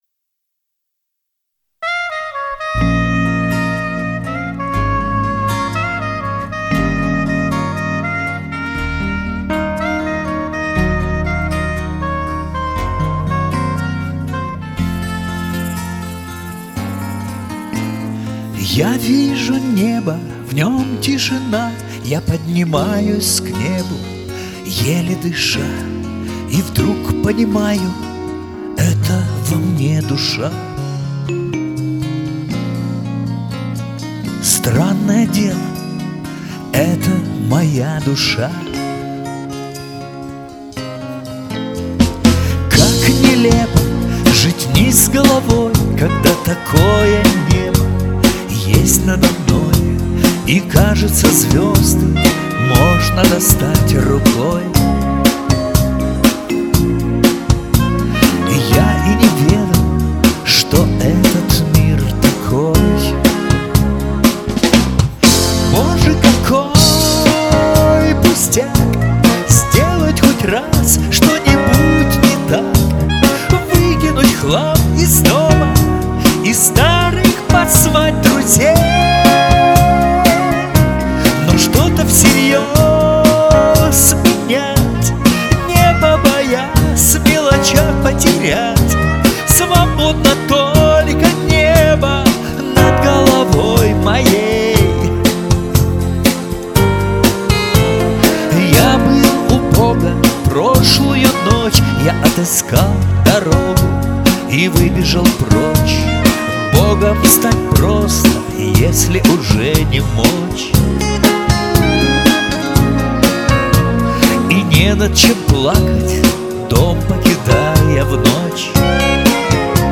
вы тут, оба-два, звучите гораздо брутальнее)....